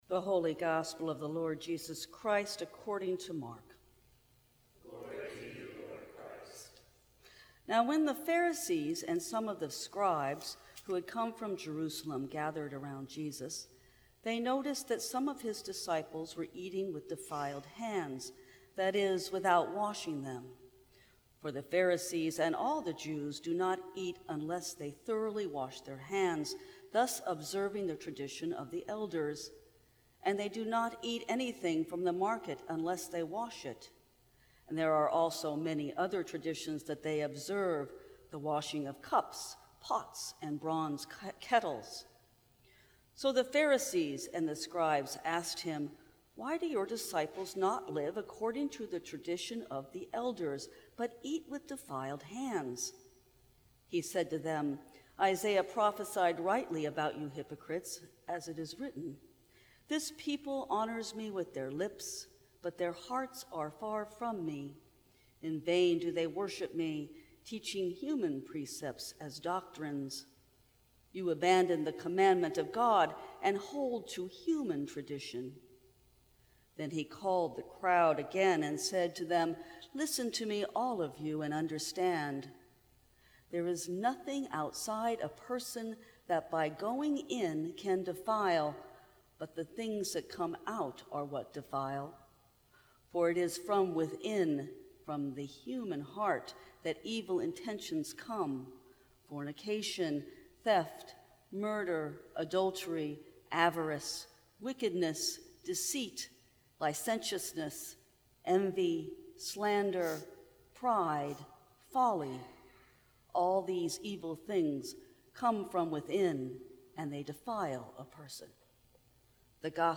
Sermons from St. Cross Episcopal Church Rules of Life Sep 06 2018 | 00:12:47 1x 00:00 / 00:12:47 Subscribe Share Apple Podcasts Spotify Overcast RSS Feed Share Link Embed